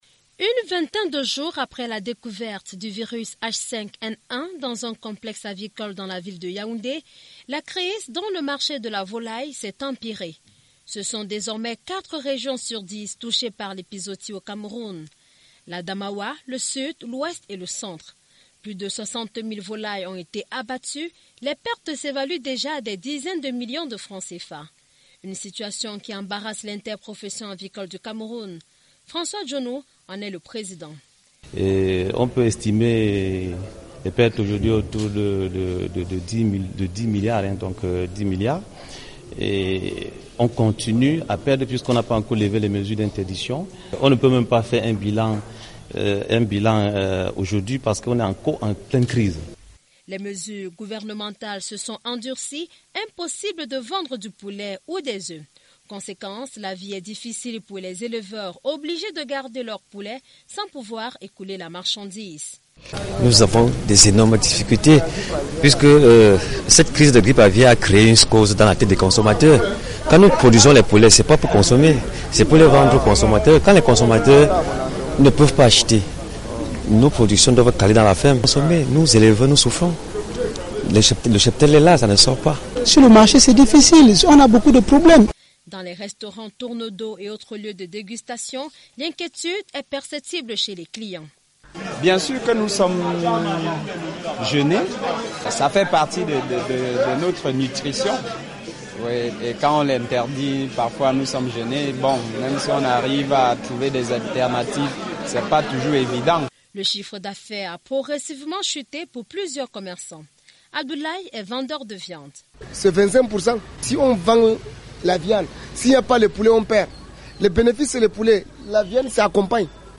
Sur place